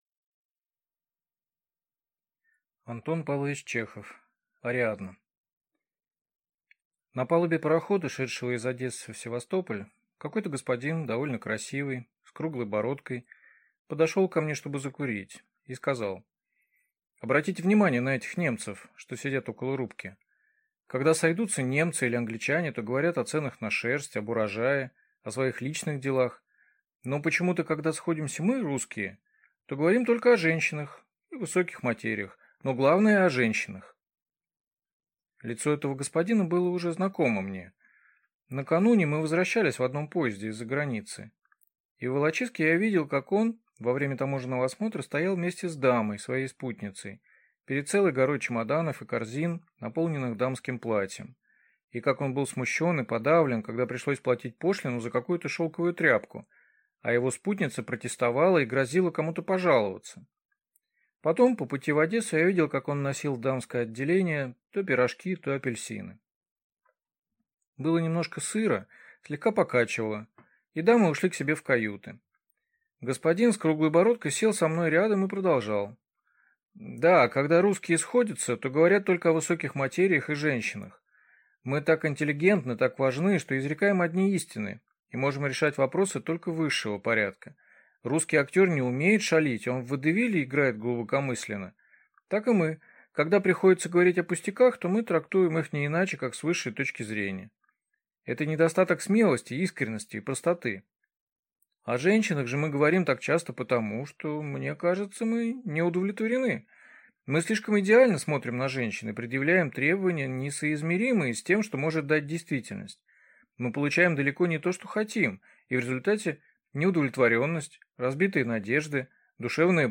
Аудиокнига Ариадна | Библиотека аудиокниг